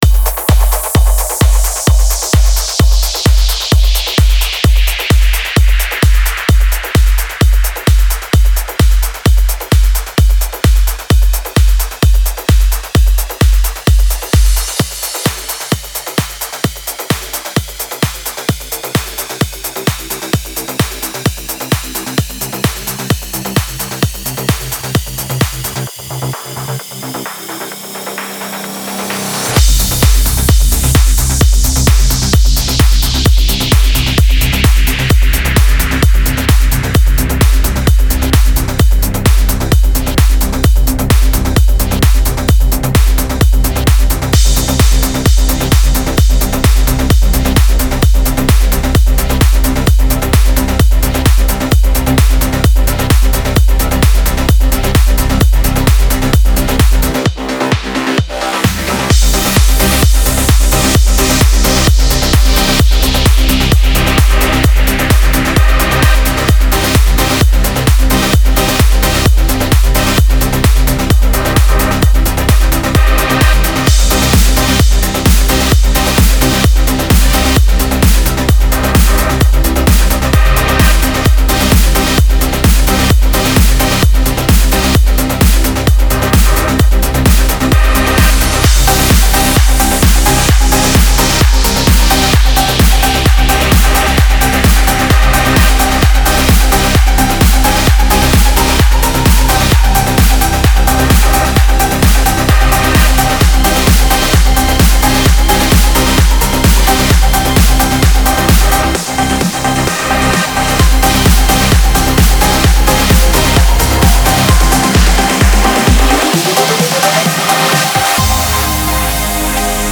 Стиль: ProgressiveTrance